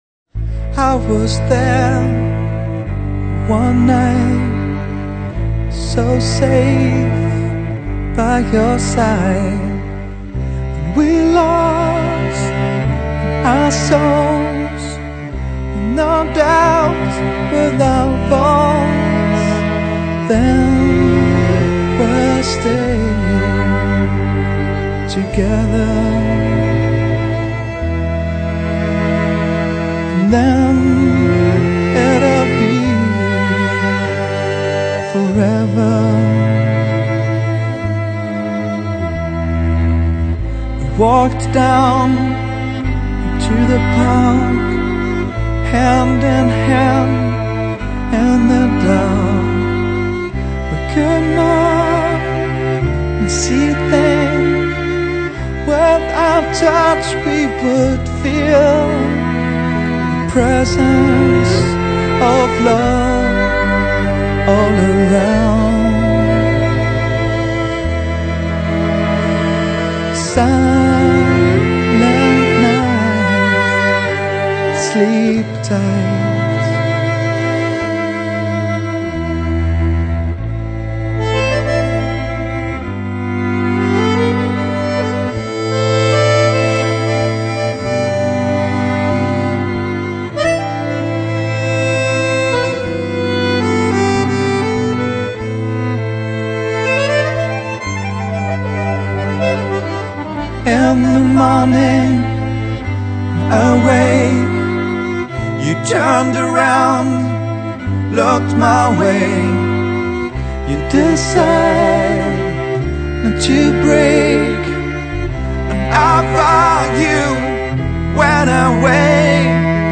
Drums and percussions
Bass
Electric guitars
Cello (#5, #6, #8 & #12)
lead and backing vocals, acoustic guitar and harp